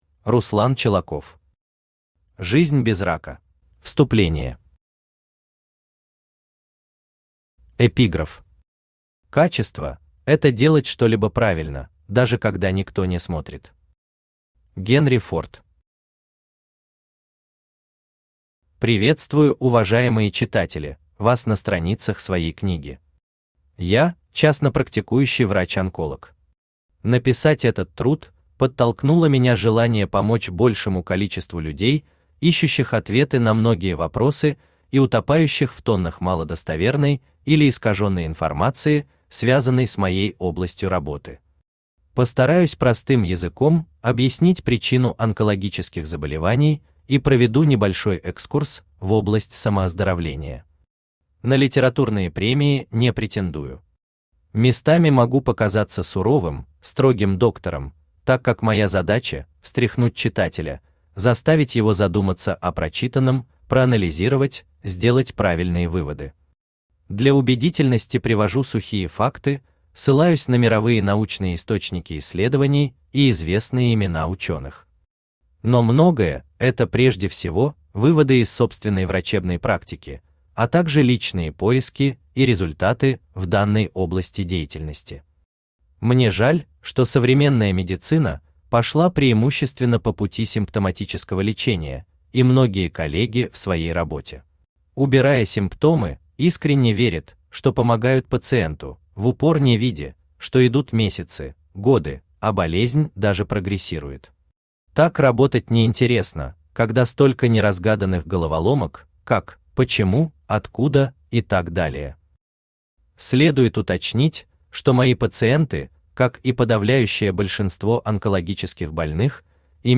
Аудиокнига Жизнь без рака | Библиотека аудиокниг
Aудиокнига Жизнь без рака Автор Руслан Сергоевич Чолаков Читает аудиокнигу Авточтец ЛитРес.